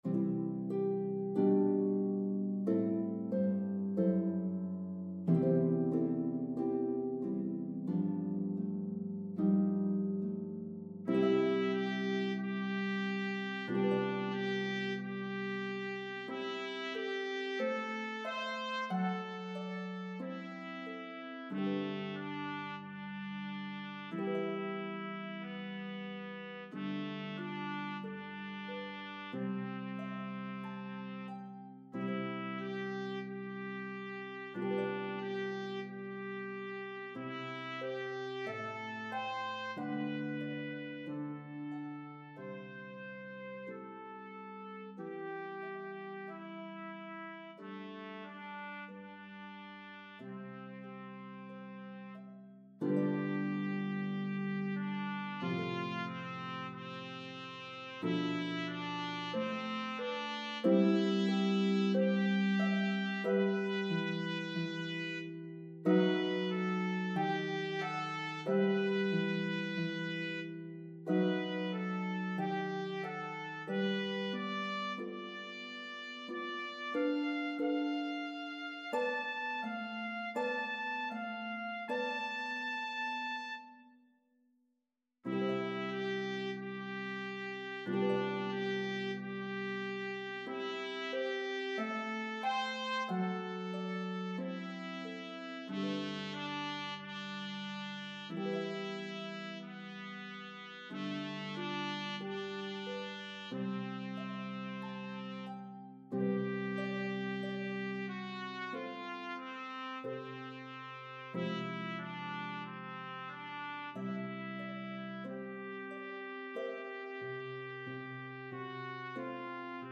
The elegant, famous melody will enchant your audiences!
Harp and Trumpet in B-flat version